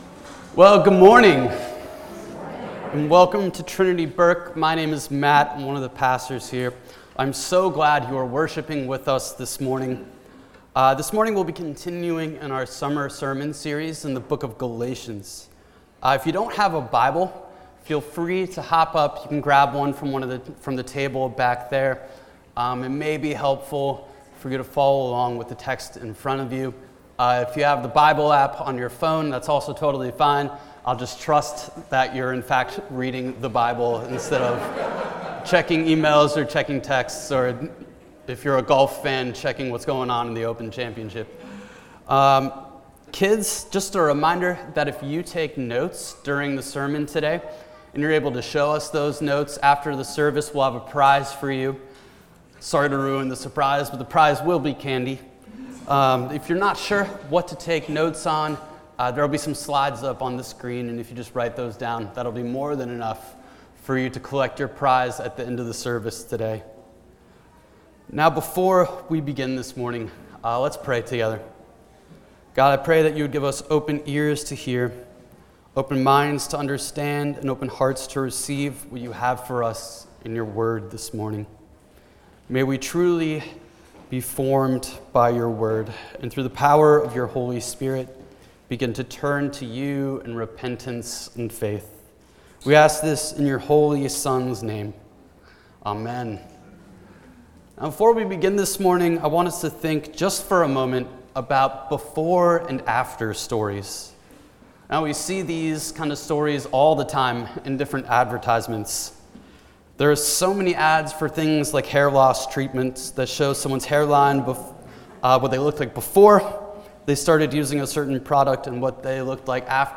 Summer 2025 sermon series in Galatians, Week 6.